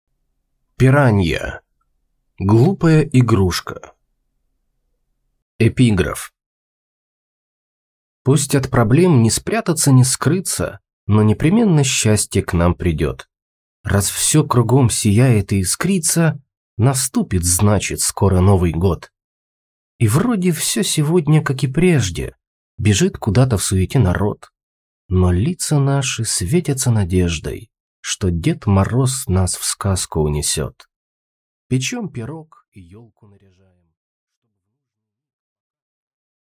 Аудиокнига Глупая игрушка | Библиотека аудиокниг